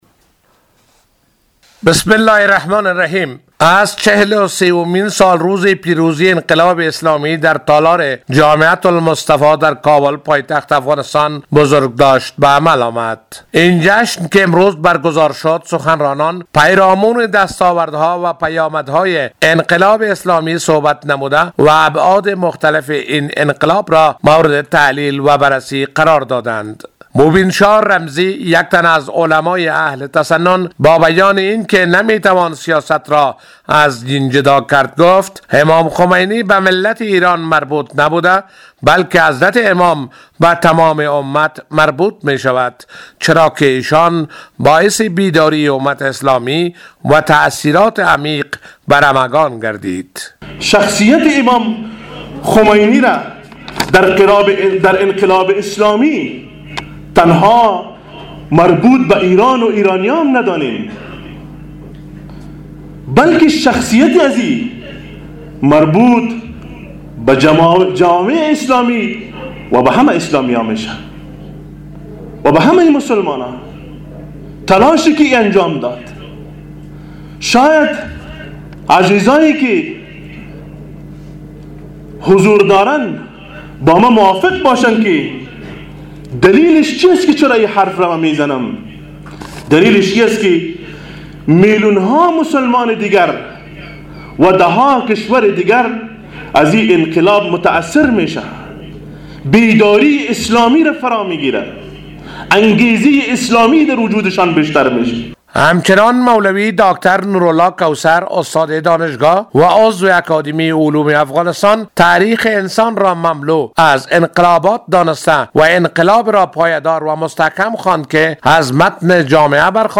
در مراسمی در تالار جامعه المصطفی در شهر کابل از چهل و سومین سالگرد پیروزی انقلاب اسلامی ایران تجلیل شد.